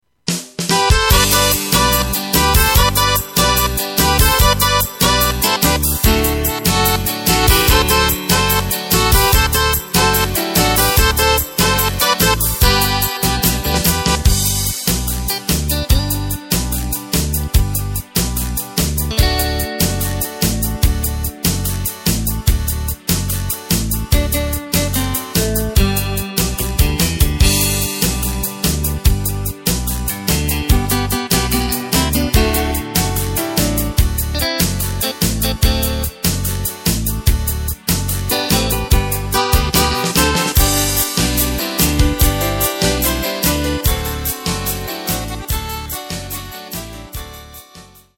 Takt:          4/4
Tempo:         146.00
Tonart:            G
Country Beat aus dem Jahr 1987!